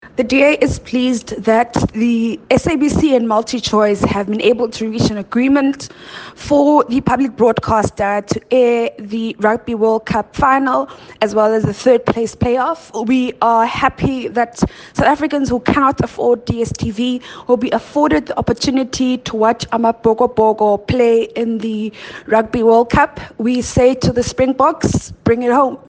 Please find attached a soundbite by Phumzile Van Damme